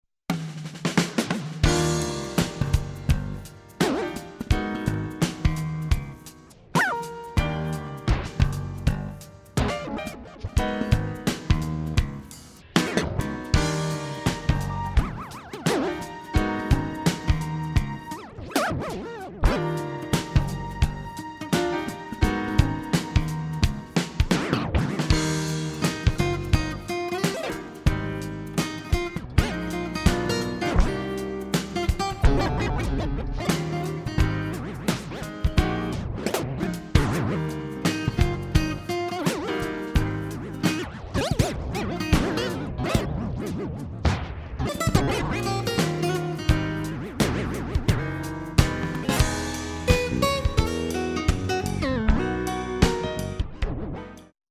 Håll i er, det är mässjazz!